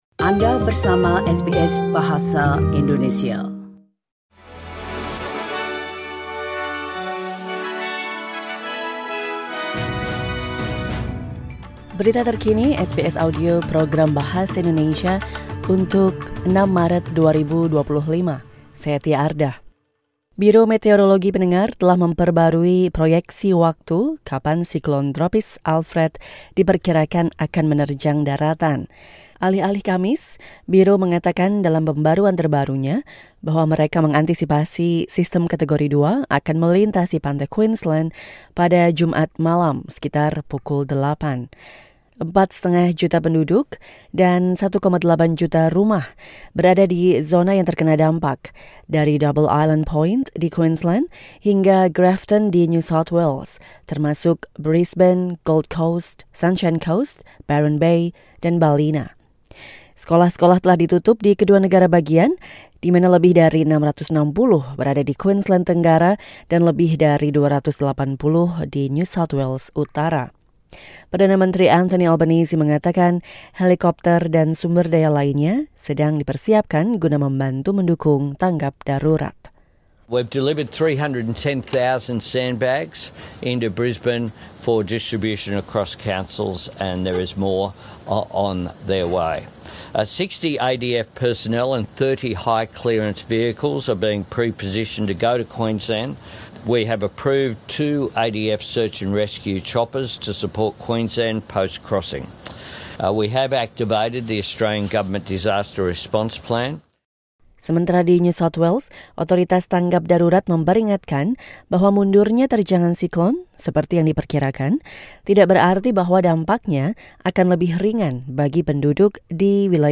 Latest news SBS Audio Indonesian program for 6 March 2025.